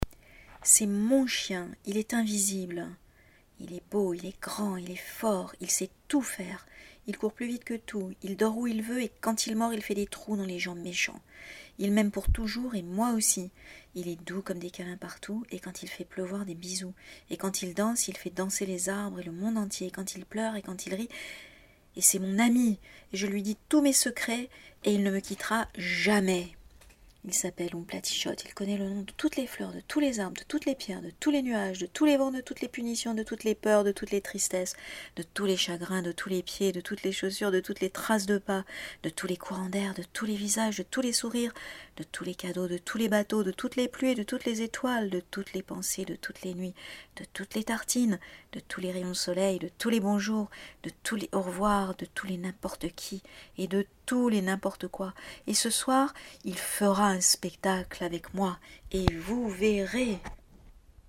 Pour finir, voici un passage du Chien invisible que j’ai lu et relu avec mes garçons. Définition de cet ami extraordinaire, dans une accumulation qu’il fallait dire le plus vite possible !